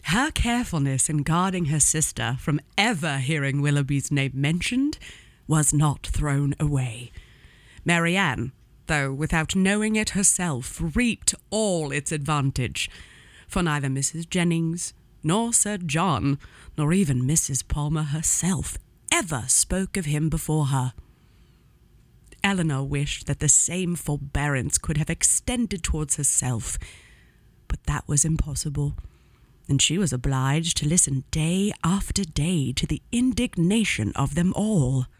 Gender: Female